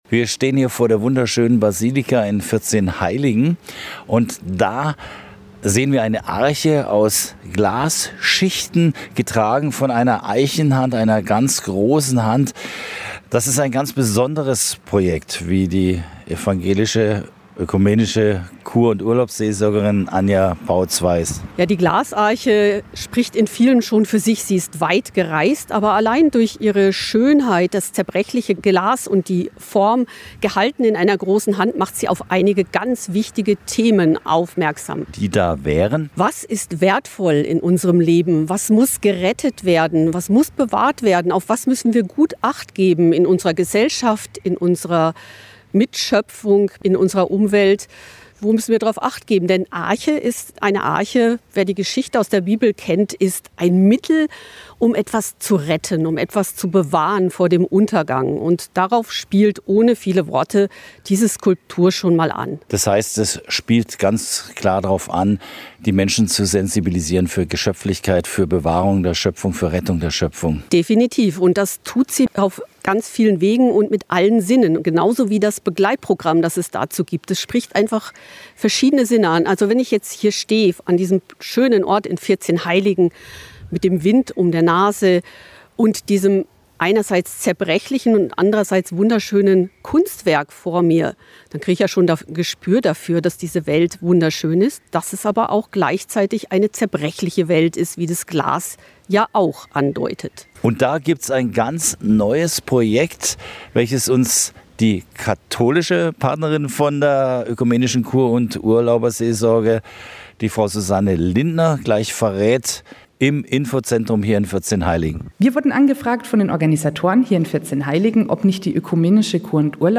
Arche_Repo_Interview-1105.mp3